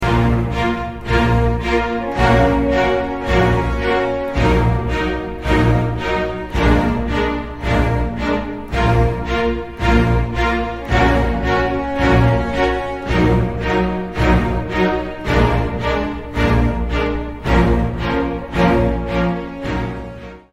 • Качество: 128, Stereo
саундтреки
без слов
инструментальные
тревожные